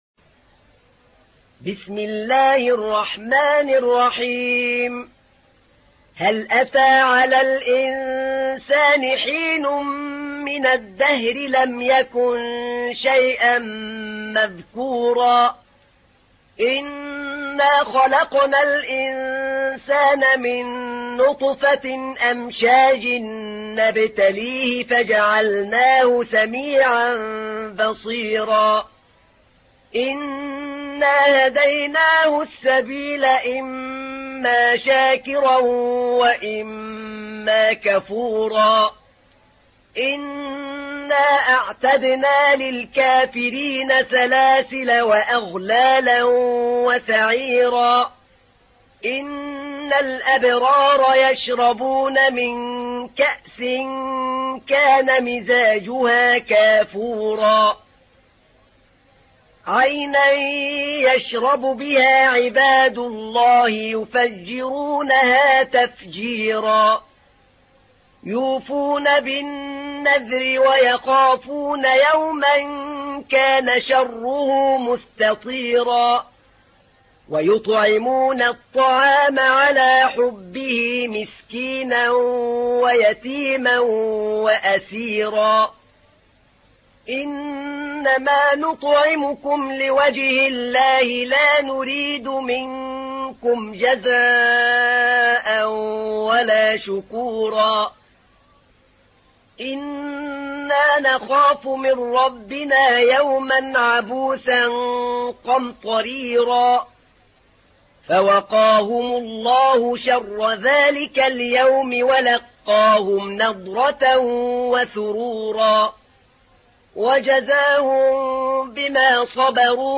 تحميل : 76. سورة الإنسان / القارئ أحمد نعينع / القرآن الكريم / موقع يا حسين